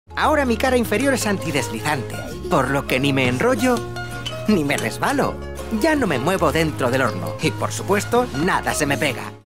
European Spanish Voice over.
kastilisch
Sprechprobe: Sonstiges (Muttersprache):
My voice is warm, smooth, friendly, authoritative, clear, enthusiastic and most importantly, believable. I can be warm and deep but also energetic and fresh.